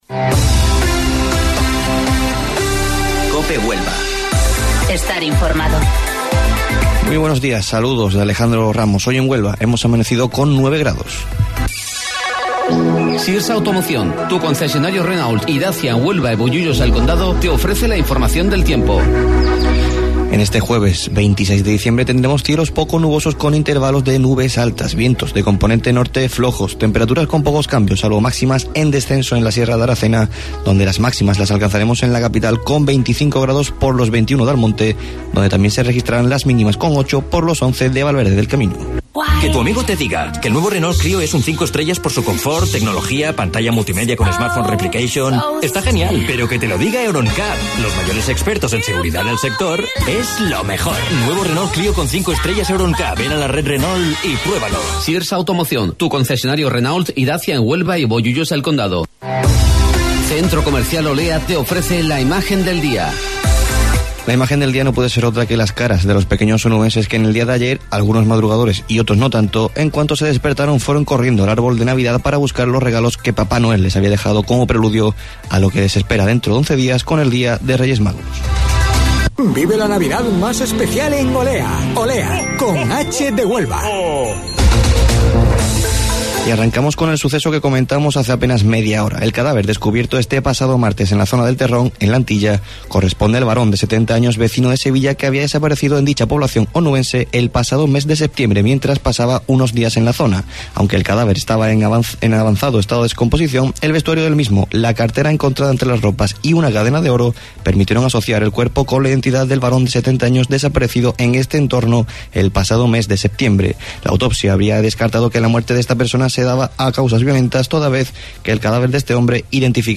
AUDIO: Informativo Local 08:25 del 26 Diciembre